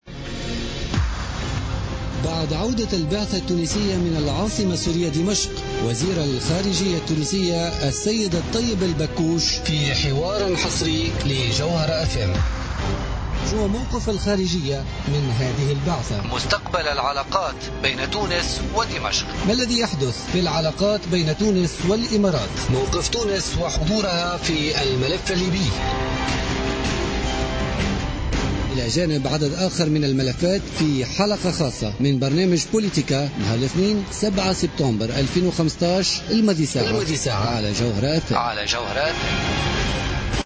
حوار حصري مع وزير الخارجية الطيب البكوش